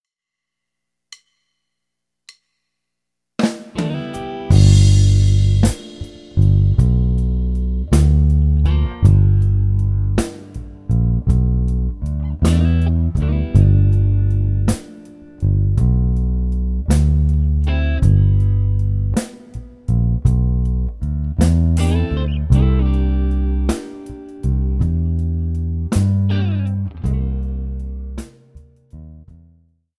Guitare Basse